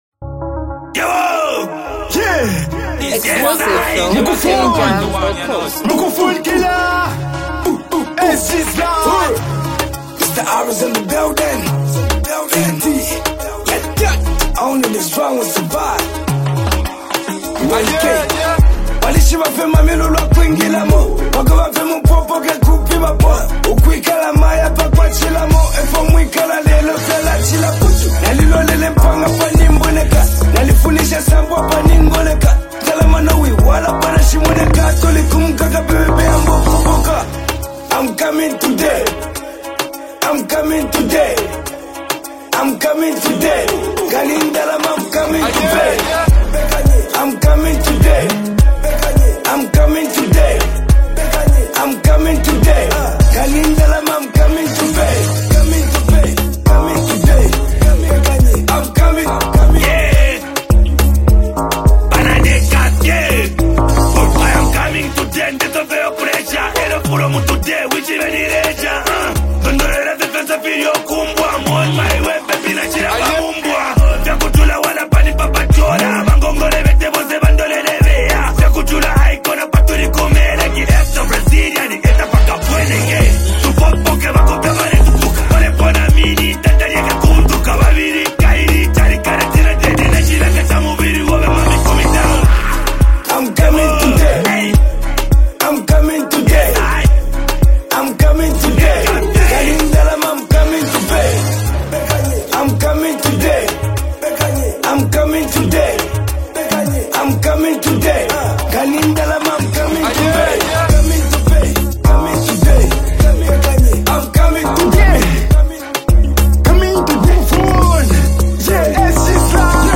an uplifting and motivational track